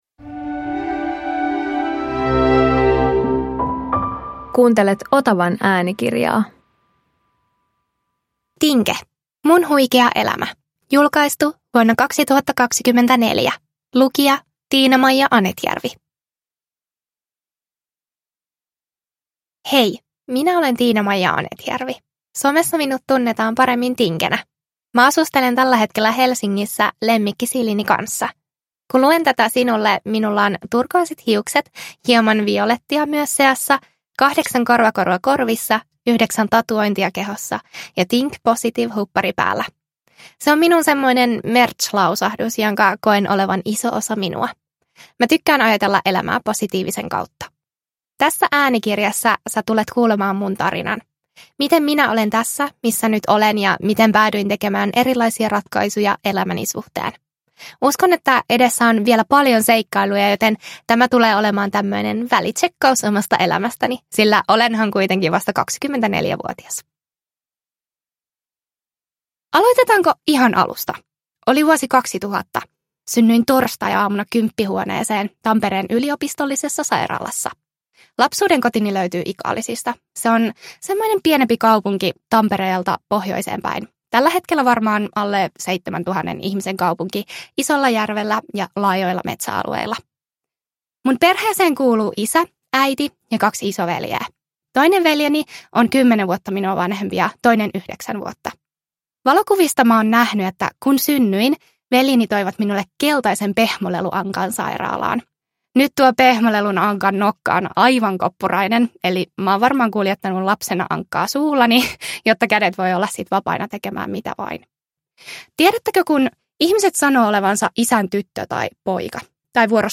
Äänikirjasarjassa tutut vaikuttajasuosikit kertovat itsestään kuin hyvälle kaverille.